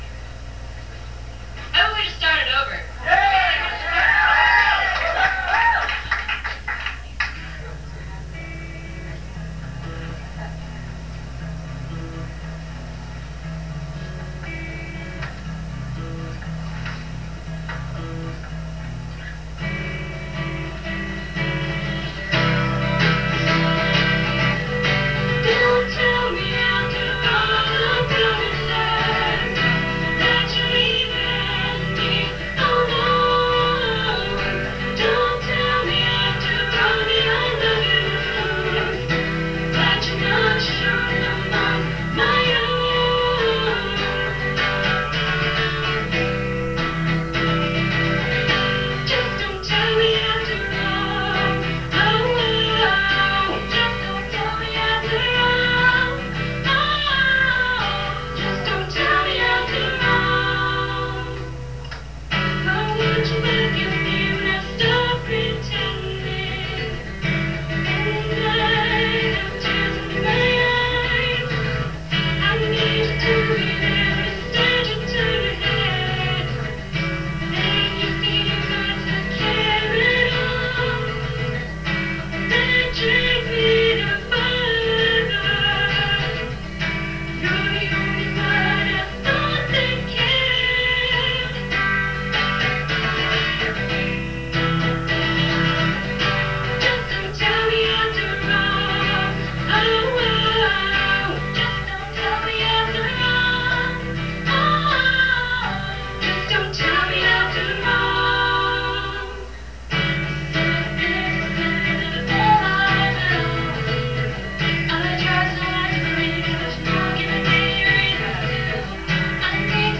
Live at Open Mic